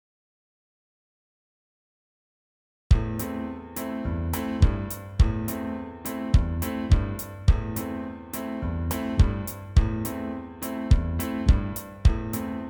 זכור לי שיש כזה מקצב באורגן, אז הכנתי סקיצה מהזיכרון.